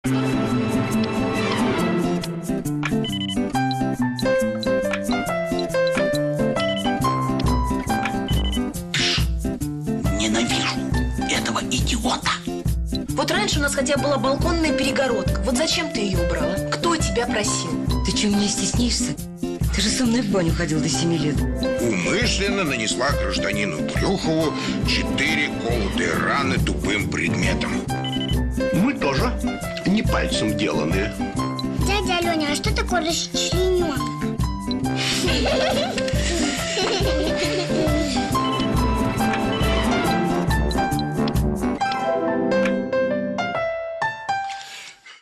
Аудио заставка